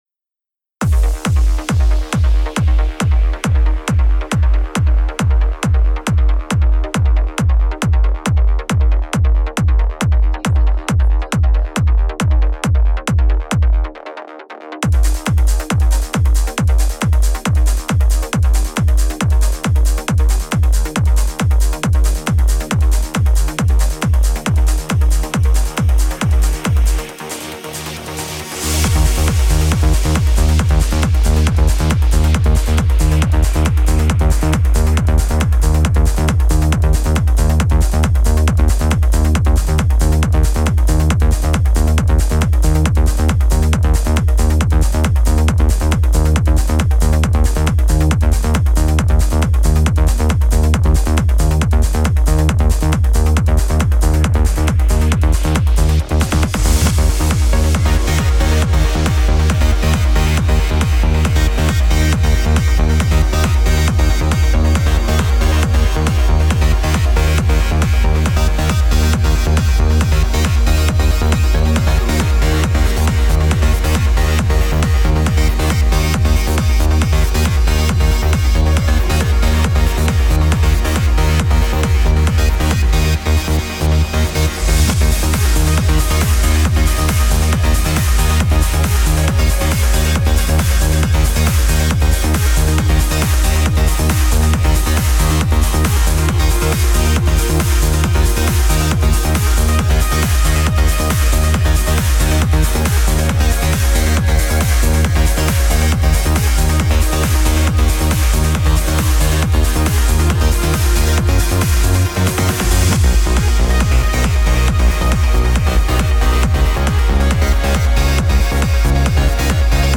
epic driving trance track